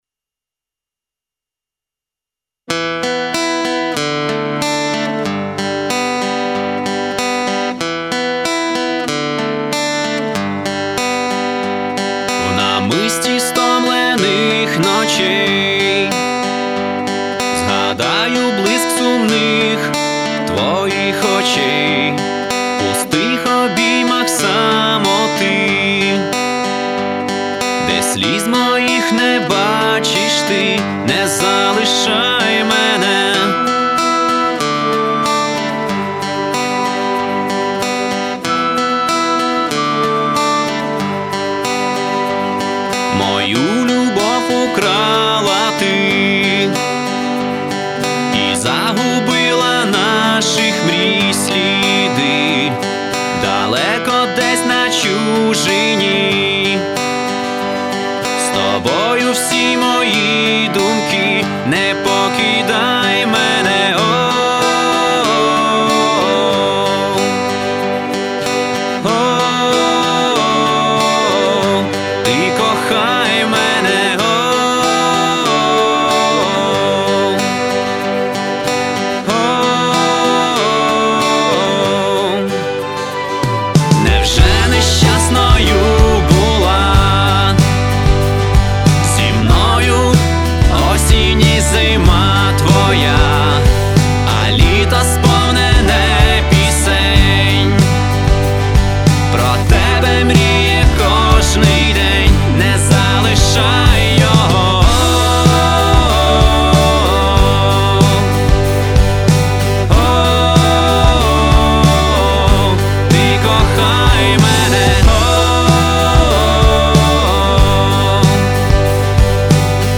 Категорія: Rock